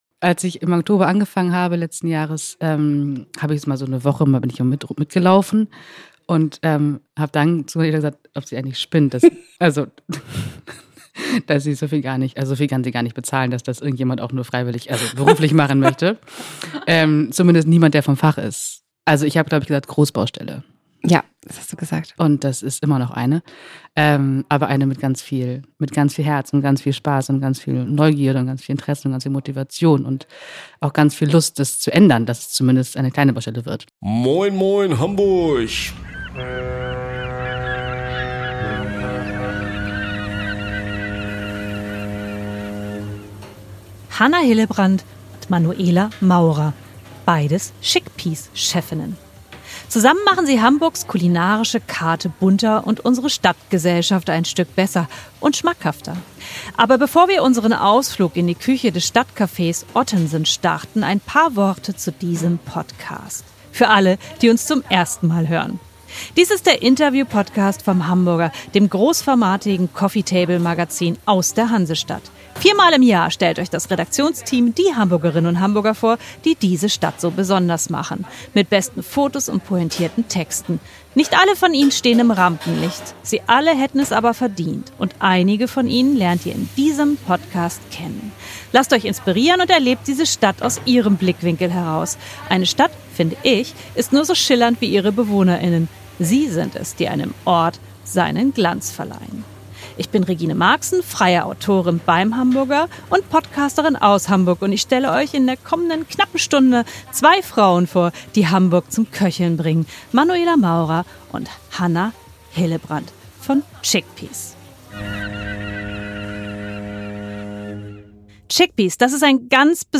Im Hintergrund wird manchmal gearbeitet, Ihr hört es.